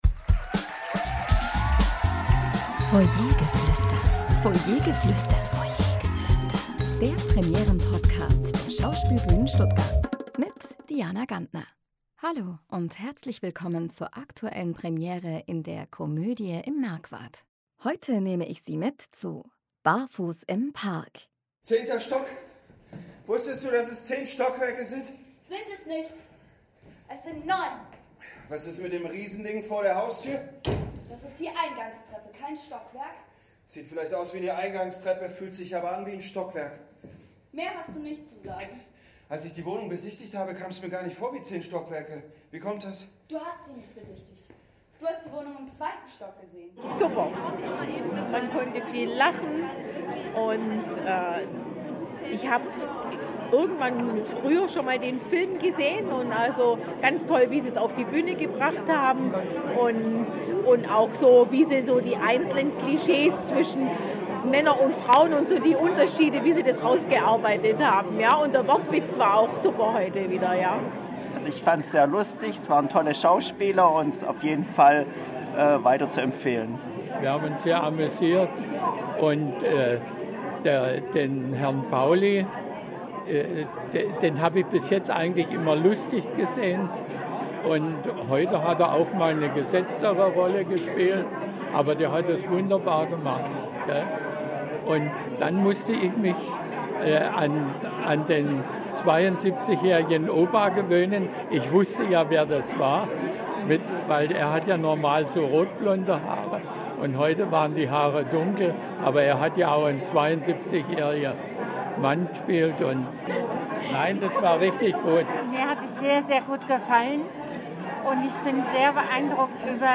Publikumsstimmen zur Premiere von “Barfuß im Park”